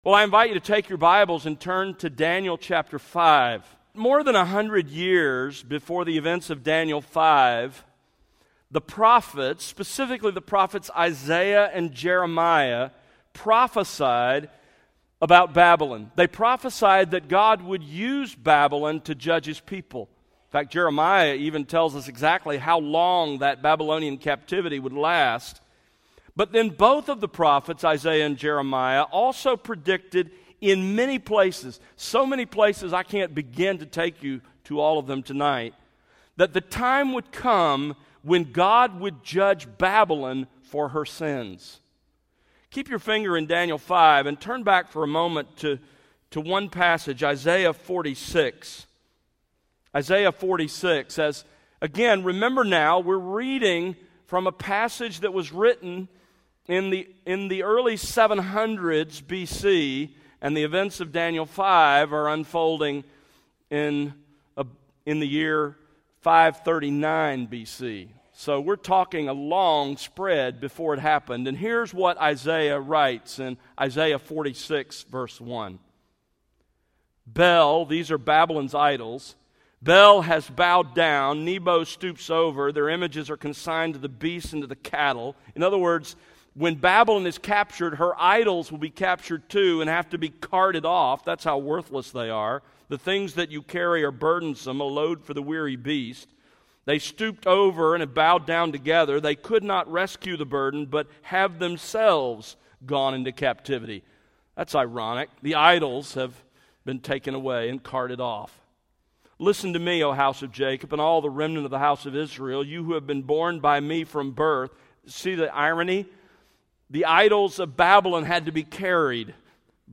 Sermons | The World Unleashed